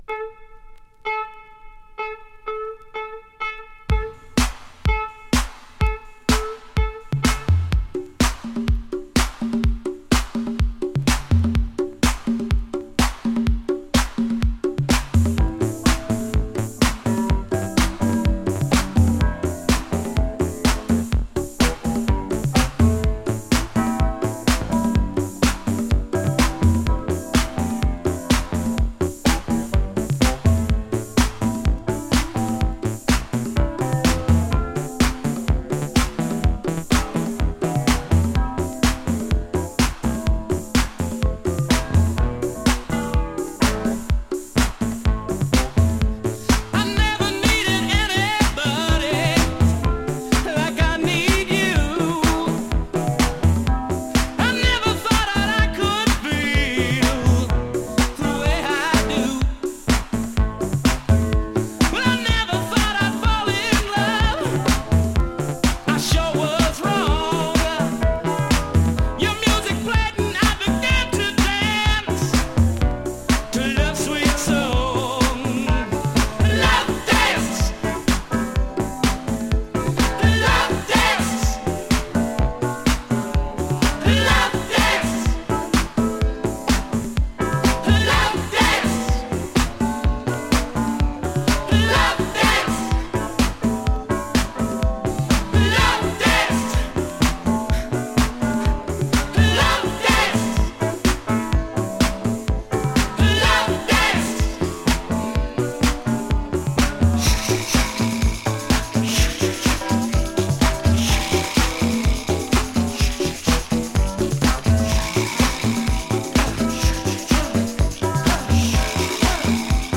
Canadian Minor Disco！